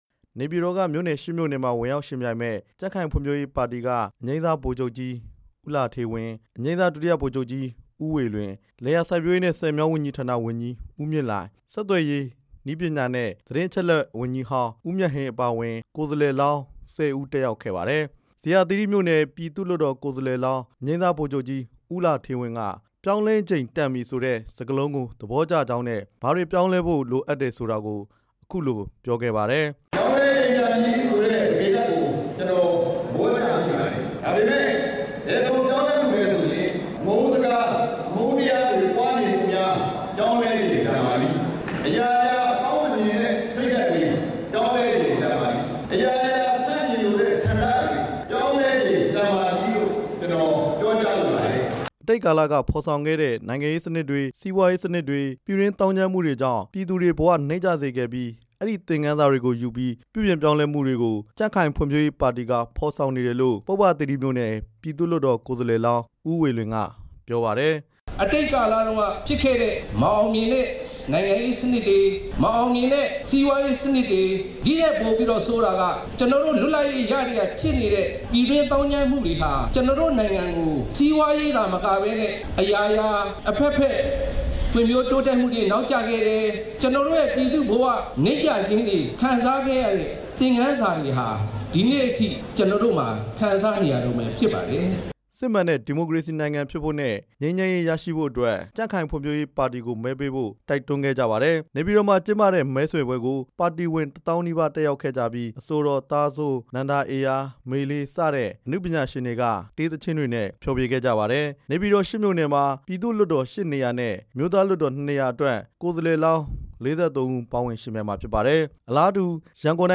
ဒဂုံမြို့သစ်အရှေ့ပိုင်း ကြံ့ခိုင်ဖံ့ွဖြိုးရေးပါတီ မဲဆွယ်ပွဲ